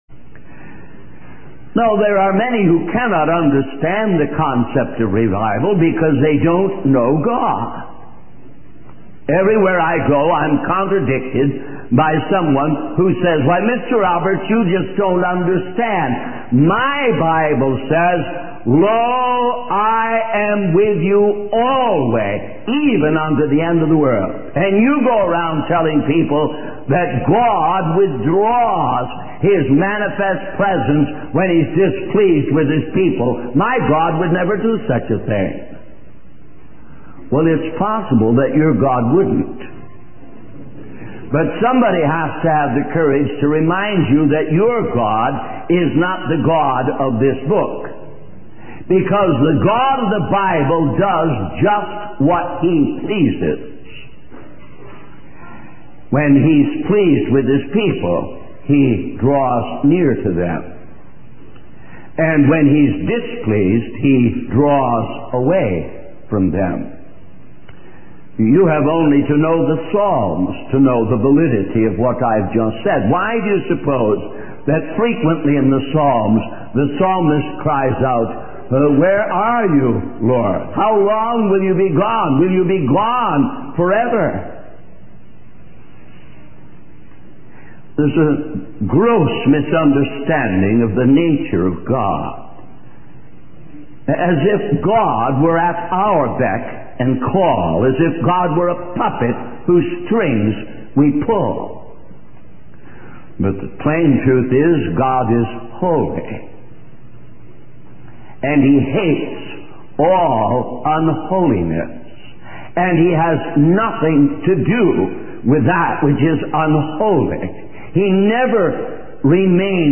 In this sermon, the speaker emphasizes the importance of adding self-control to our lives as Christians. He highlights how many people are governed by their passions and emotions, lacking self-control. The speaker challenges the audience to examine their own lives and identify any areas where they lack control.